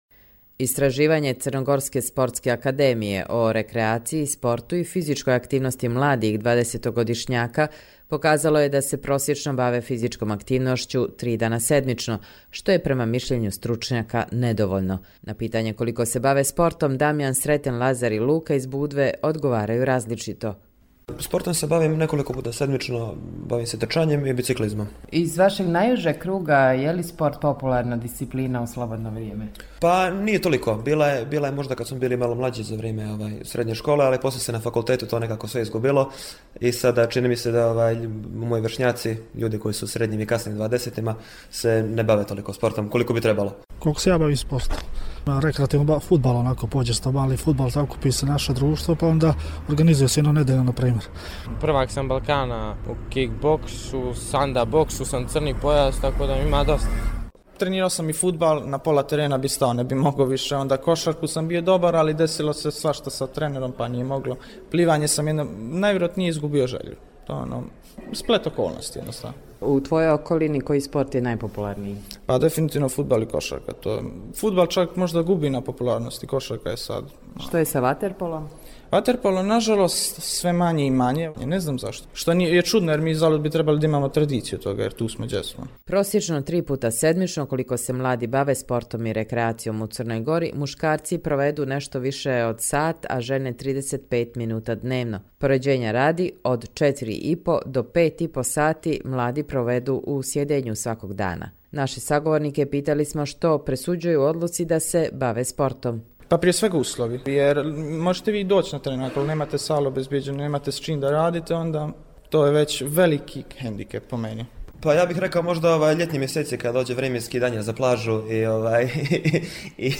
Mišljenja mladih u Budvi